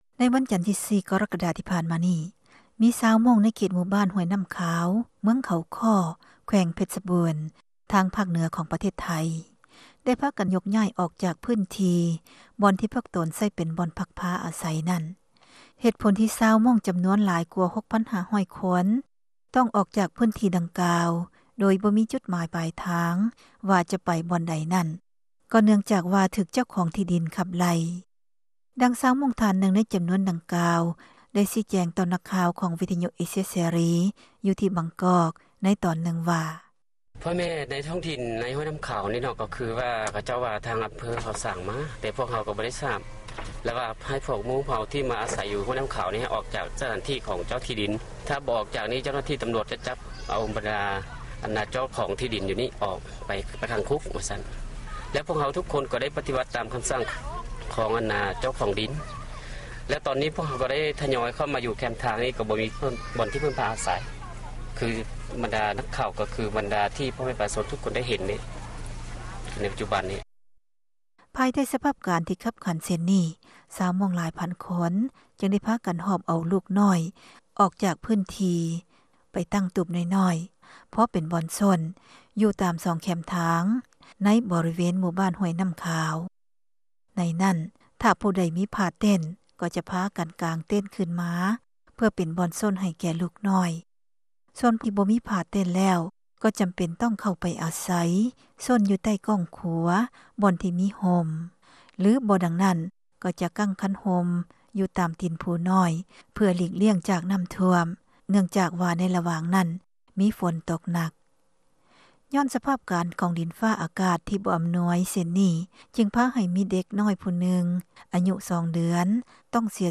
ມີຣາຍງານ ກ່ຽວກັບເລື້ອງນີ້ ມາສເນີທ່ານ ໃນຣາຍການແມ່ຍິງແລະເດັນນ້ອຍປະຈຳສັປະດາ